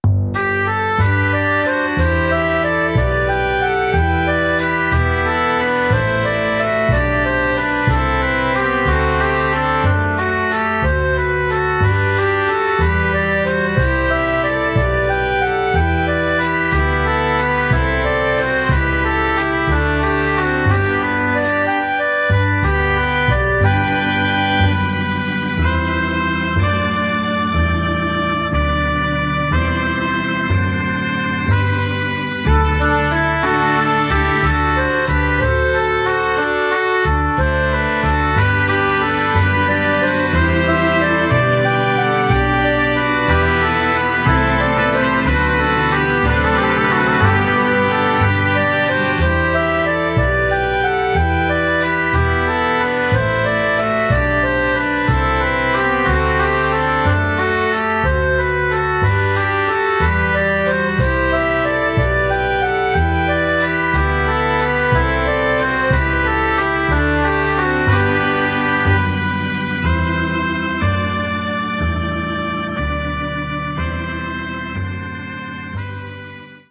Barroco
polifonía
cantata
contrapunto
coral
sintetizador